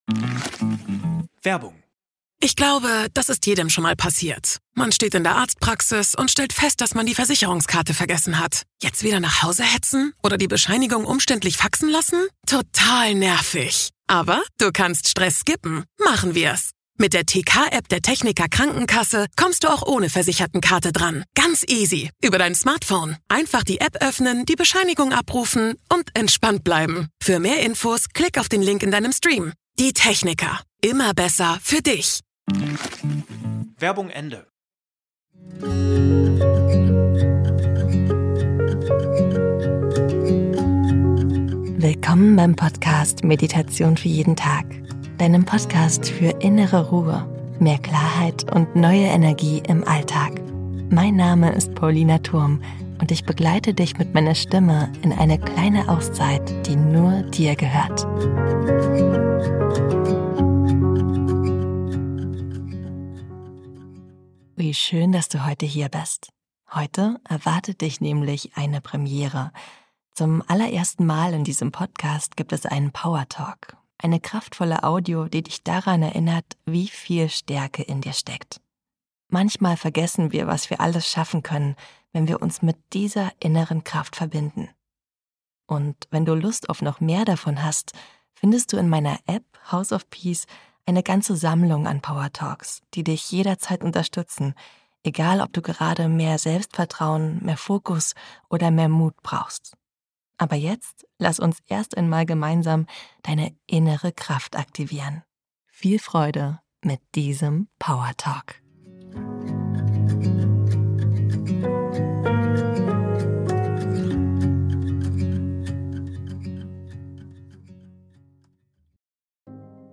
Dieser Powertalk hilft dir, genau diese innere Stärke zu spüren. Mit kraftvollen Worten und bestärkender Musik löst du dich von Zweifeln und aktivierst deine innere Kraft.